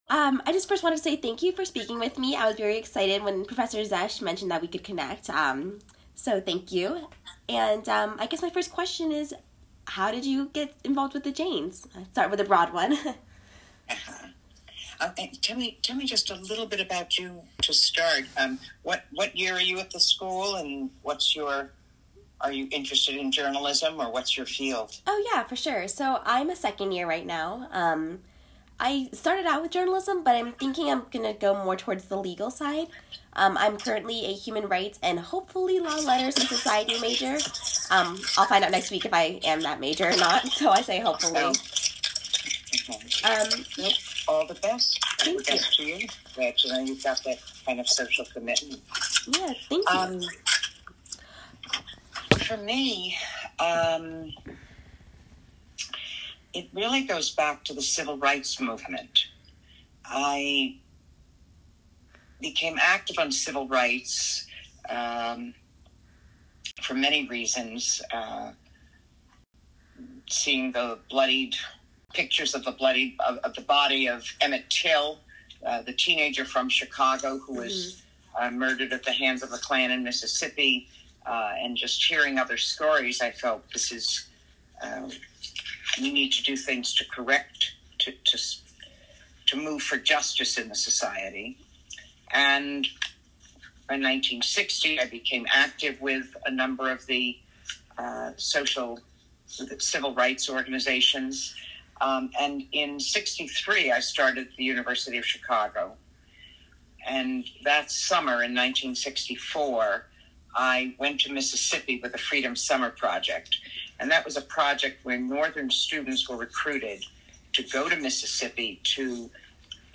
Ida Noyes Hall